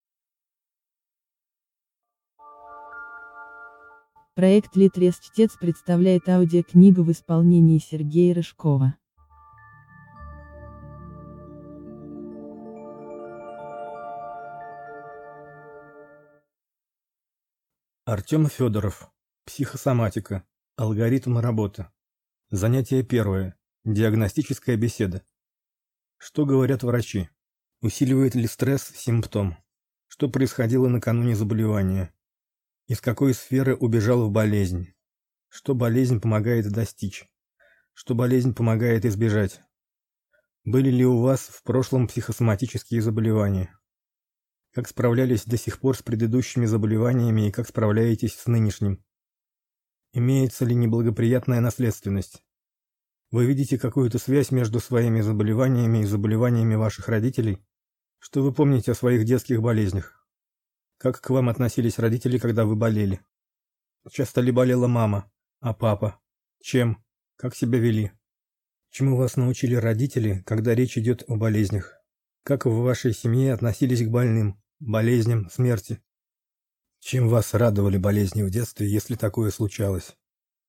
Аудиокнига Психосоматика. Алгоритмы работы | Библиотека аудиокниг